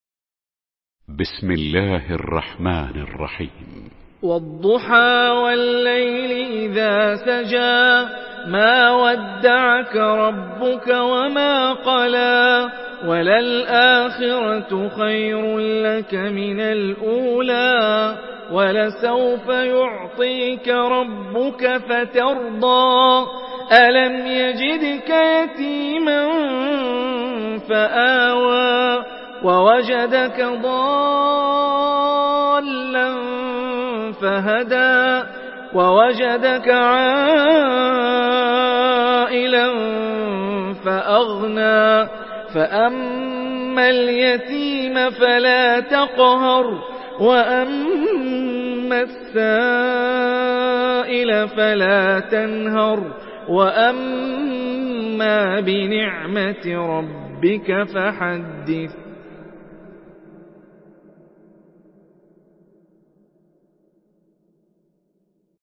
Surah Ad-Duhaa MP3 by Hani Rifai in Hafs An Asim narration.
Murattal